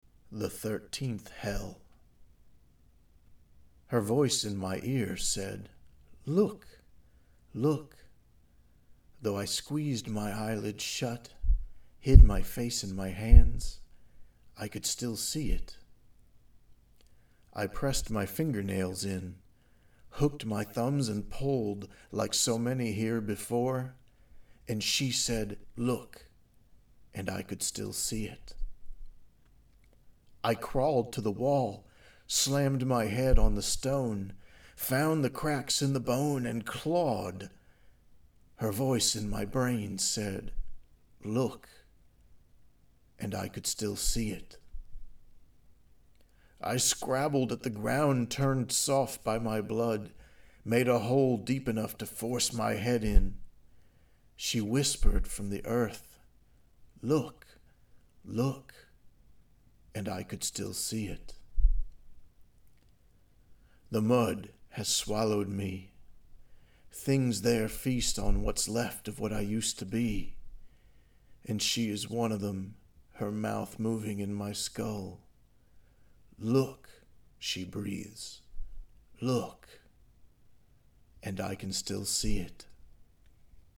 2008 Halloween Poetry Reading